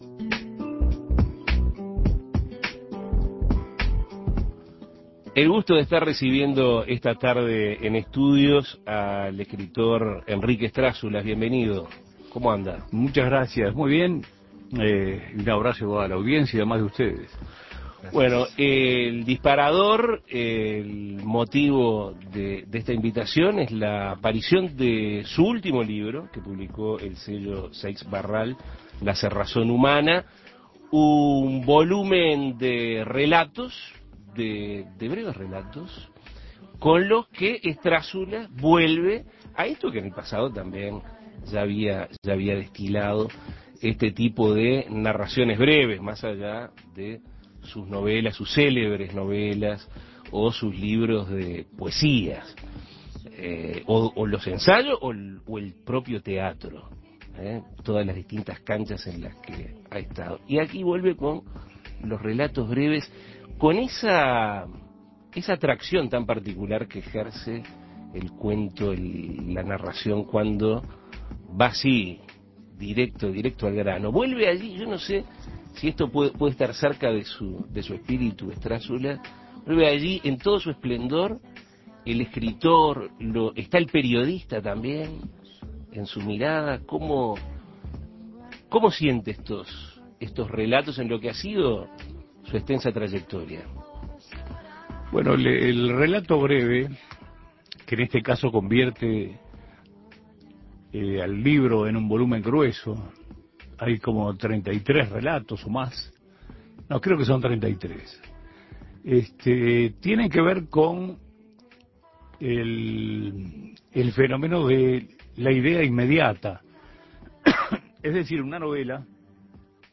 Escuche la entrevista con el escritor Enrique Estrázulas